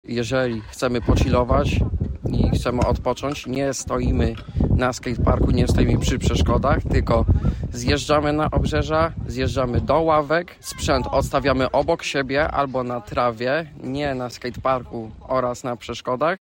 Ważne jest także prawidłowe obchodzenie się ze sprzętem w czasie nieużytkowania go, dodaje.